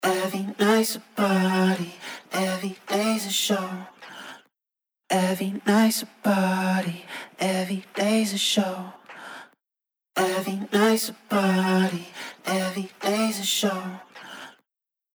Octavox | Vocals | Preset: 12 String
Octavox-Eventide-Male-Vocal-12-String.mp3